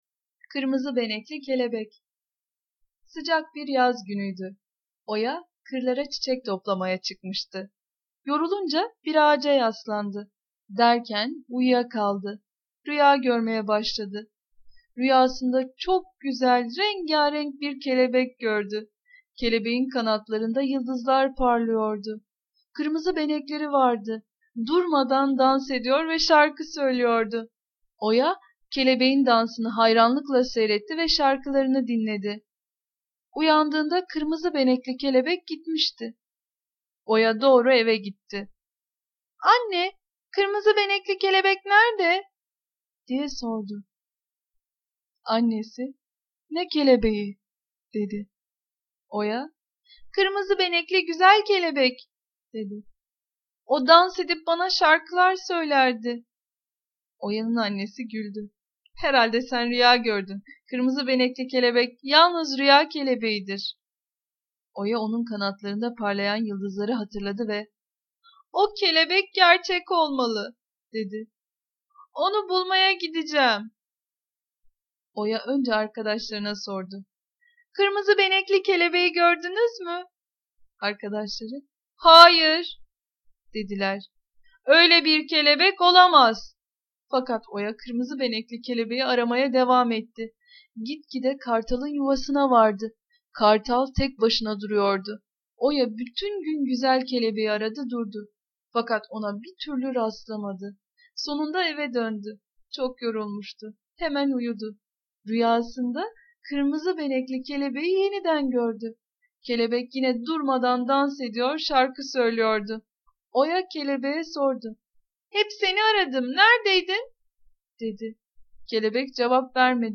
Kırmızı benekli kelebek sesli masalı, mp3 dinle indir
Kategori Sesli Çocuk Masalları